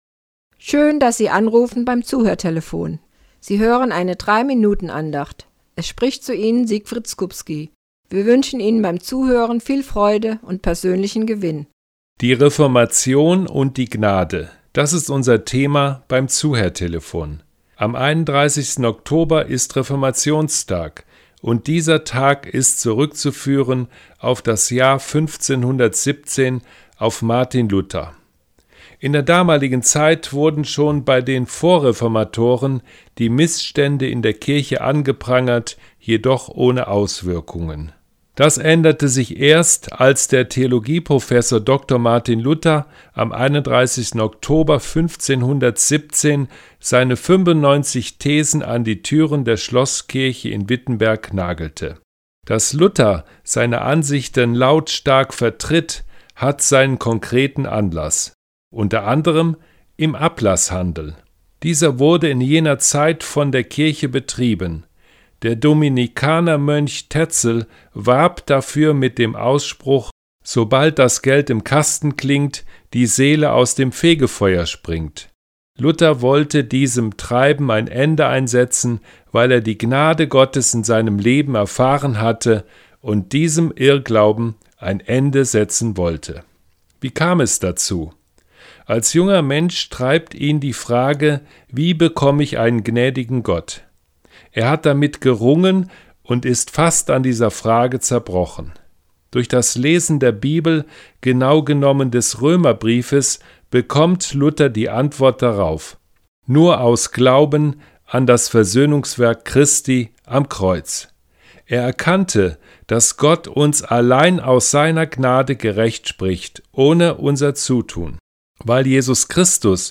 WEB-ANDACHT Gönnen Sie sich einen Moment Pause und hören Sie zu.